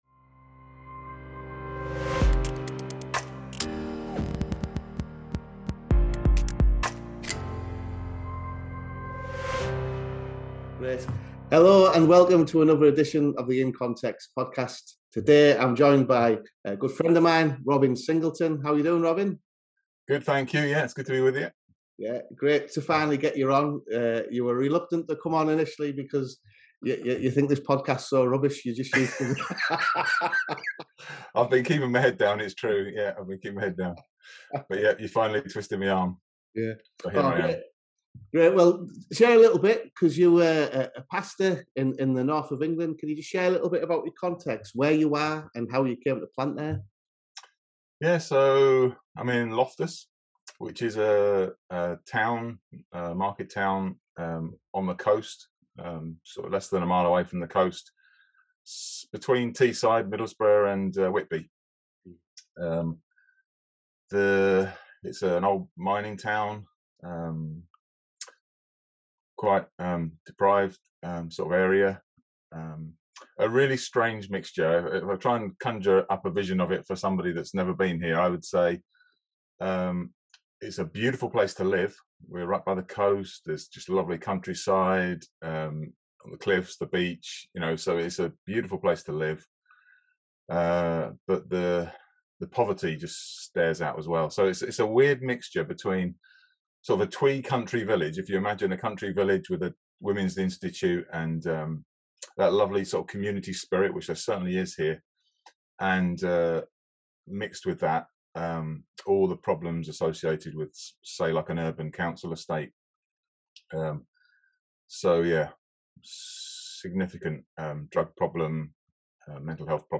Sharing the Gospel & Your Life: An Interview